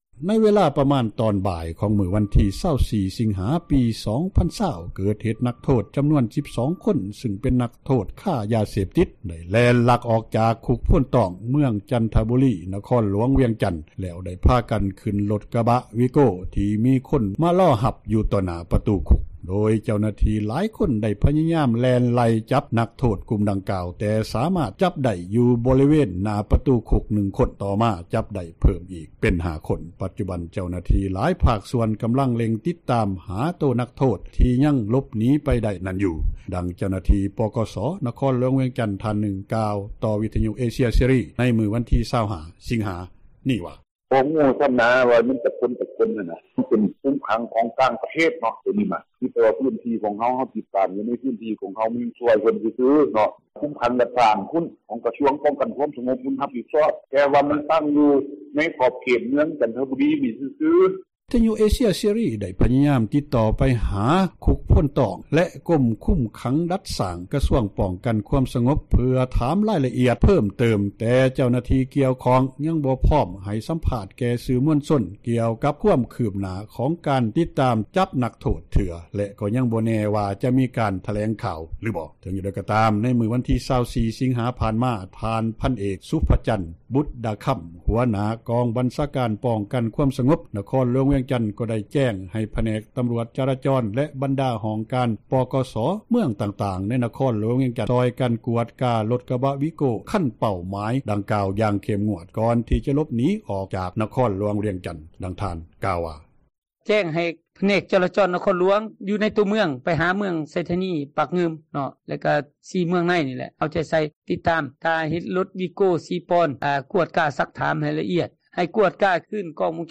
ດັ່ງເຈົ້າຫນ້າທີ່ ປກສ ນະຄອນ ຫຼວງວຽງຈັນ ທ່ານນຶ່ງ ກ່າວຕໍ່ເອເຊັຽເສຣີ ໃນມື້ວັນທີ 25 ສິງຫາ ນີ້ວ່າ: